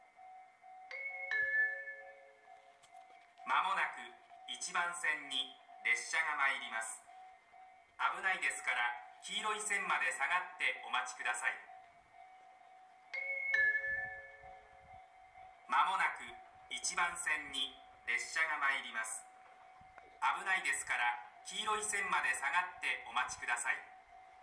この駅では接近放送が設置されています。
１番線羽越本線
接近放送普通　秋田行き接近放送です。